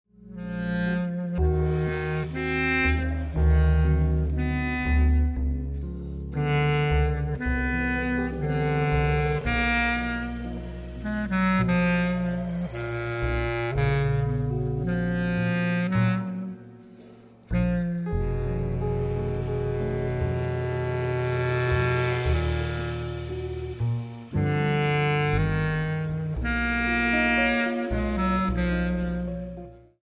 saxophone, bassclarinet
piano
guitar
drums, percussion